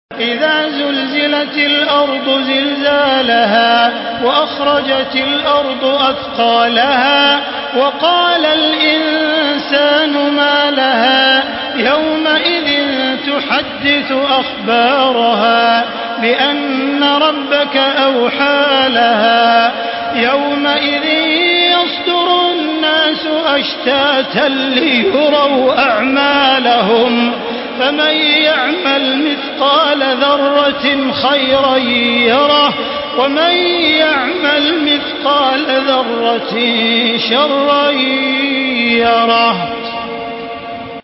Surah Zelzele MP3 by Makkah Taraweeh 1435 in Hafs An Asim narration.
Murattal Hafs An Asim